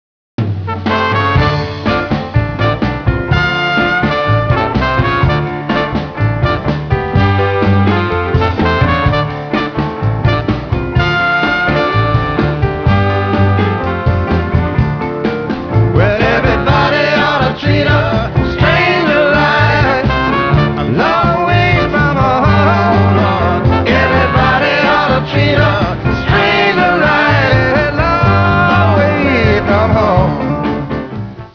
Vocals, guitar, piano, foot
Guitar, back up vocals, mandolin
Tenor saxophone
Trumpet
Bass
Harmonica
Violin